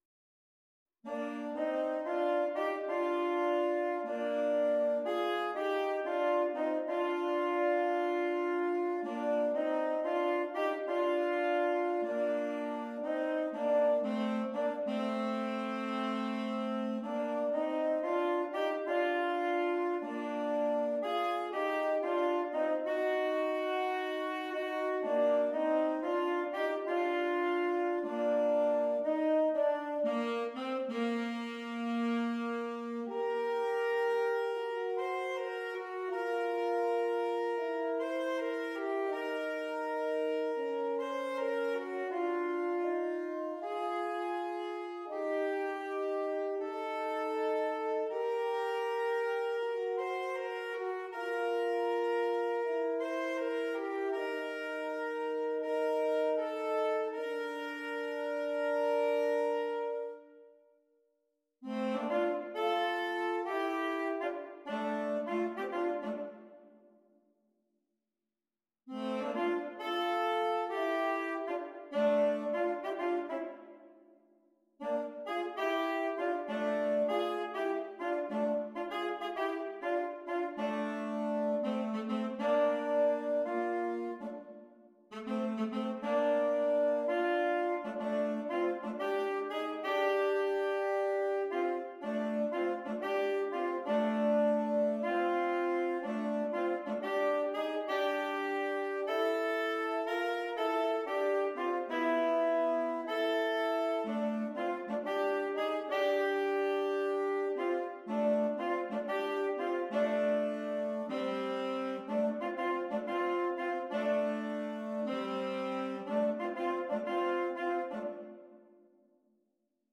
2 Alto Saxophones
A varied assortment of styles is offered for your merriment.